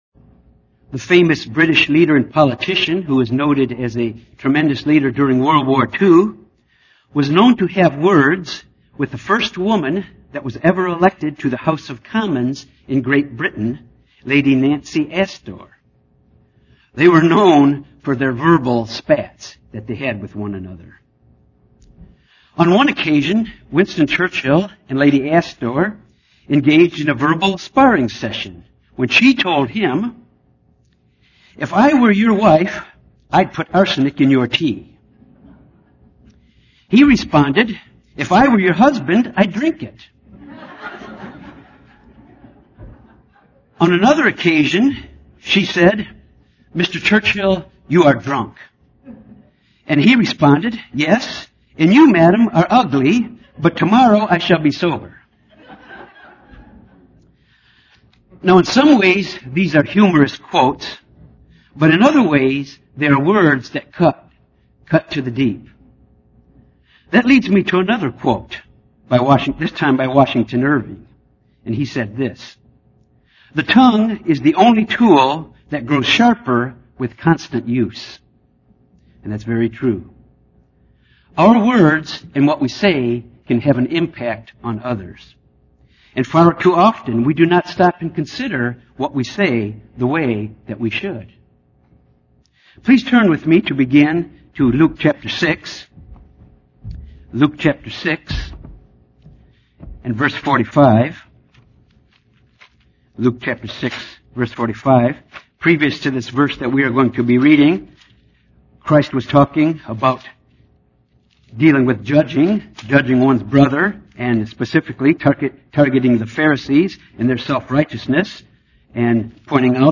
Given in Little Rock, AR
UCG Sermon Studying the bible?